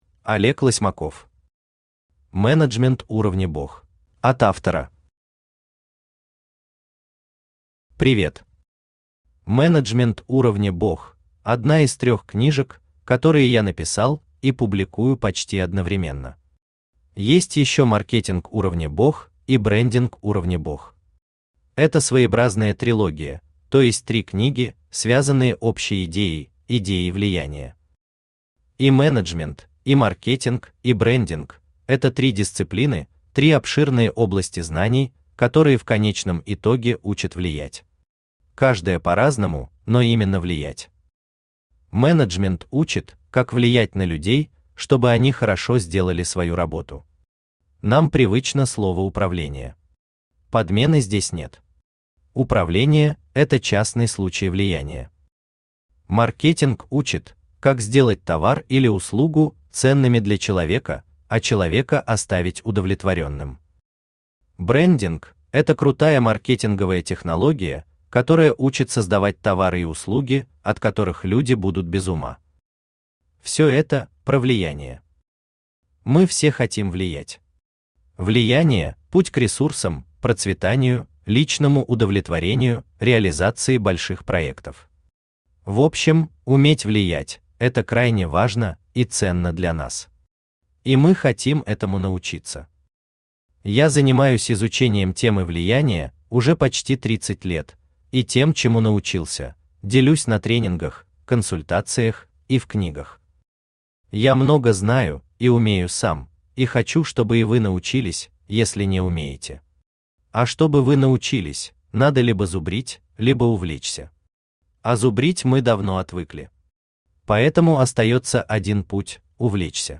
Аудиокнига Менеджмент уровня БОГ | Библиотека аудиокниг
Aудиокнига Менеджмент уровня БОГ Автор Олег Лосьмаков Читает аудиокнигу Авточтец ЛитРес.